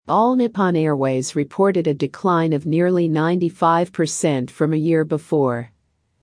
【ややスロー・スピード】
❖ reported a:a の発音はあいまい母音(＝schwa[ə])です。
❖ decline of:o の発音はあいまい母音(＝schwa[ə])です。
❖ from a:a の発音はあいまい母音(＝schwa[ə])です。